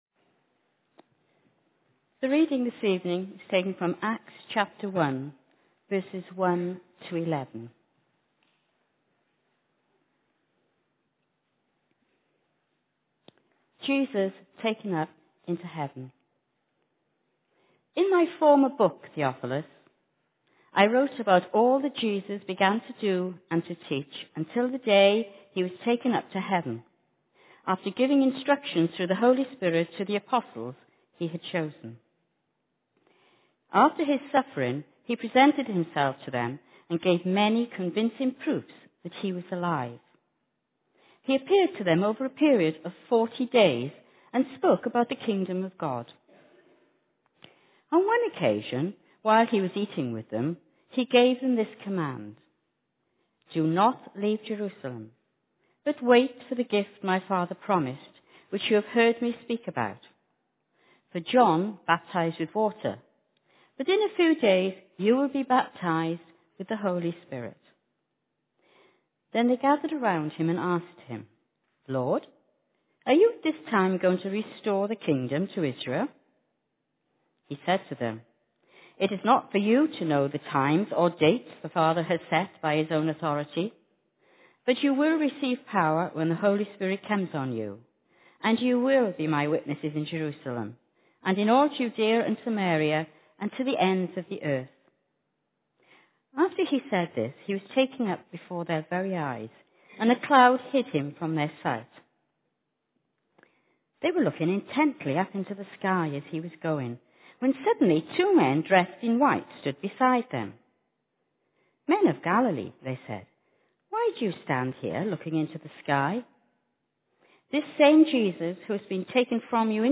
Genre: Speech.